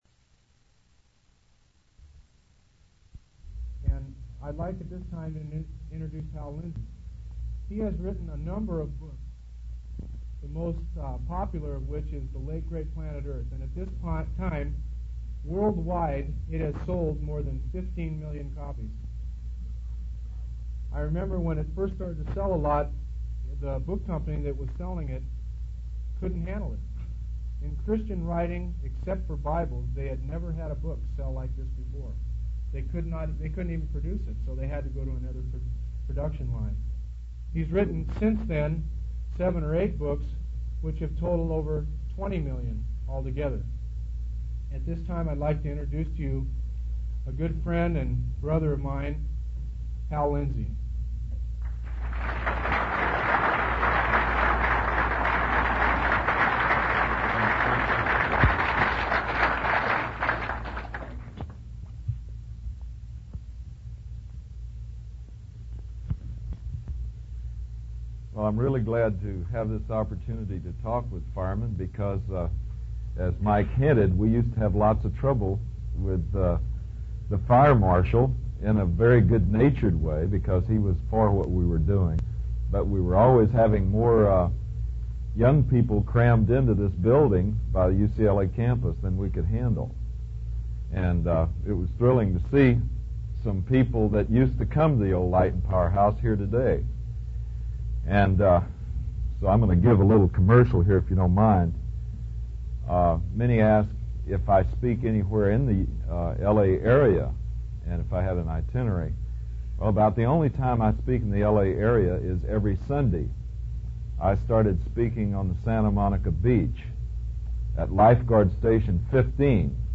In this sermon, the speaker discusses the unique perils that the current generation is facing. He mentions that there are three main perils: runaway nuclear proliferation, pollution of the air and water, and the runaway population explosion.